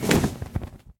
dragon_wings4.ogg